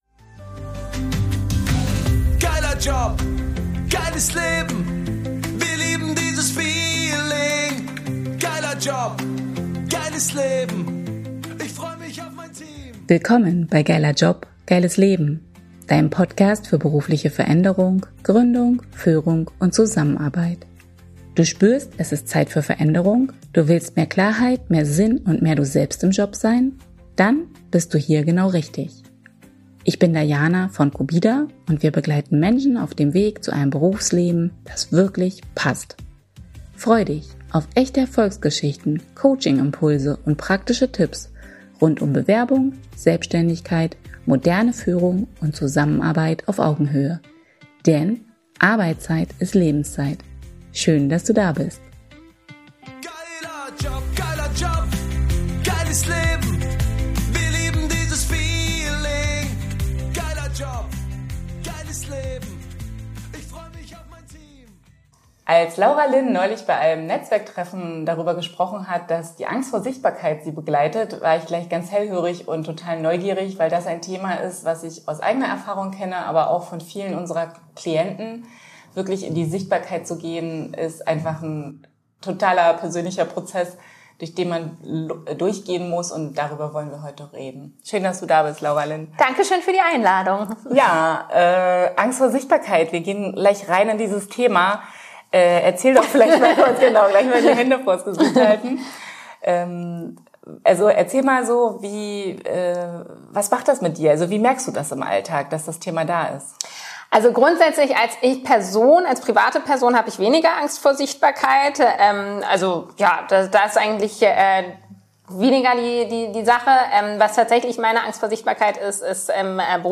#015 Angst vor Sichtbarkeit | Interview